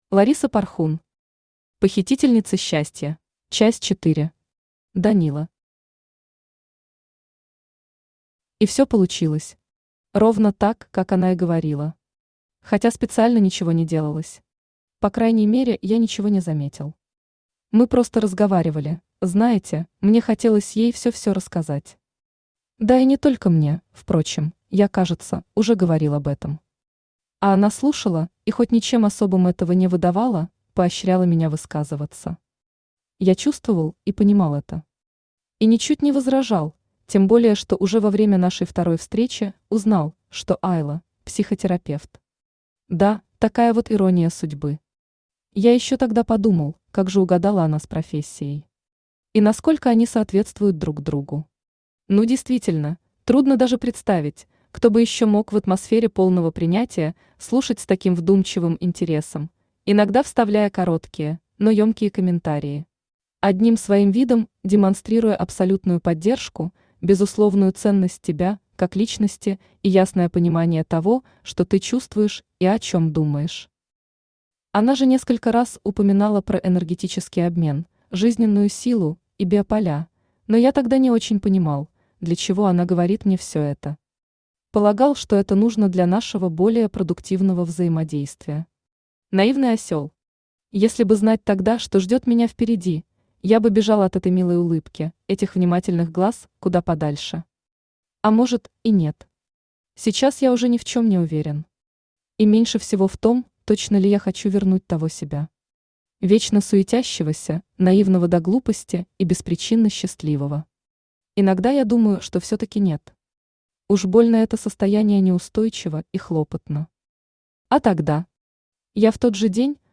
Аудиокнига Похитительницы счастья | Библиотека аудиокниг
Aудиокнига Похитительницы счастья Автор Лариса Порхун Читает аудиокнигу Авточтец ЛитРес.